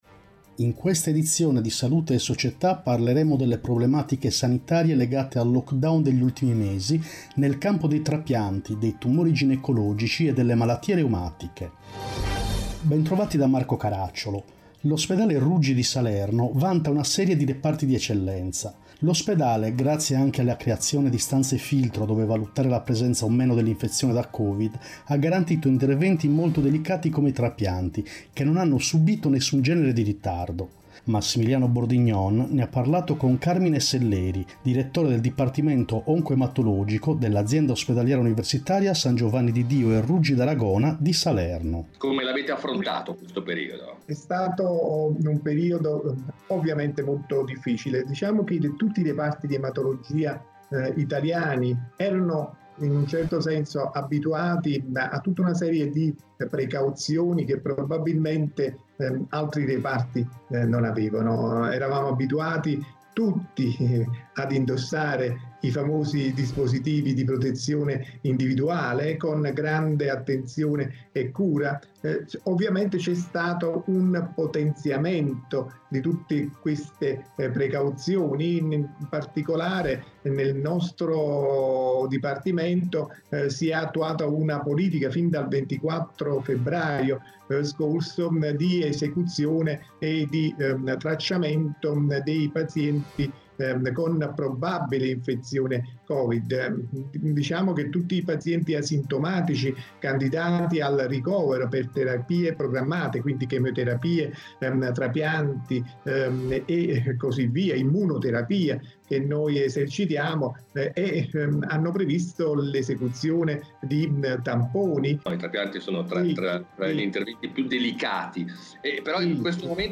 In questa edizione: 1. Covid 19, Trapianti 2. Covid 19, Tumori ginecologici 3. Covid 19, Malattie reumatiche Interviste